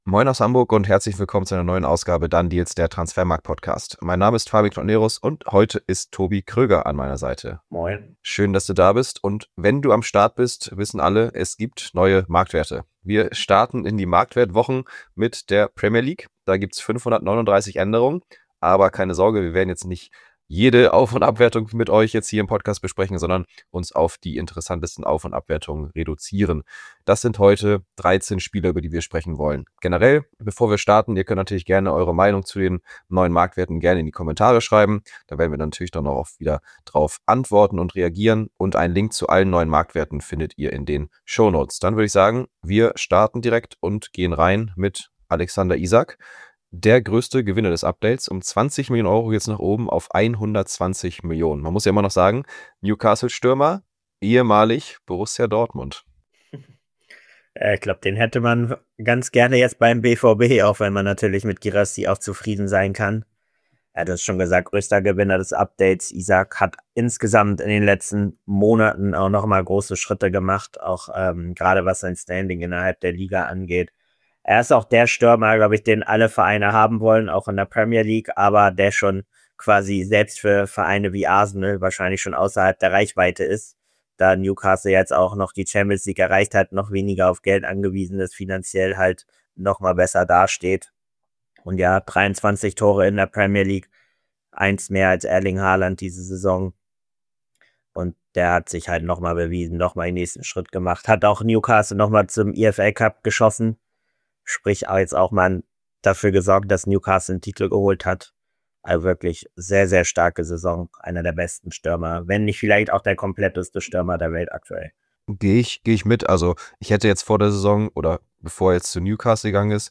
Fußball-Transfers pur! Die beiden sprechen jeden Donnerstag zu den aktuellen News im Transfer-Business.